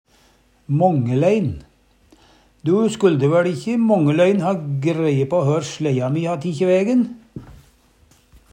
DIALEKTORD PÅ NORMERT NORSK mångelein på mange måtar, på ein eller annan måte Eksempel på bruk Du skulle væL ikkje mångelein ha greie på hør sLejja mi kan ha tikji vægen.